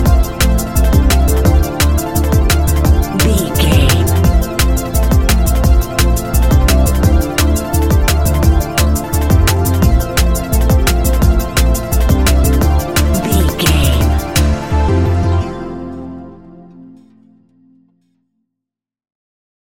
Ionian/Major
electronic
techno
trance
synthesizer
synthwave